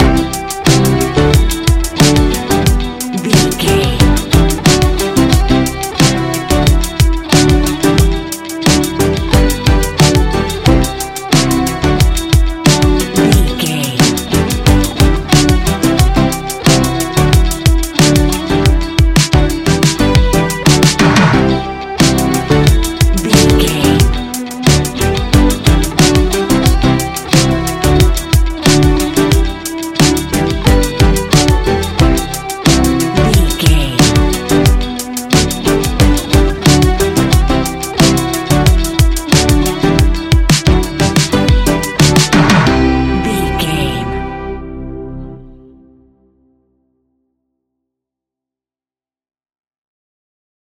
Ionian/Major
ambient
downtempo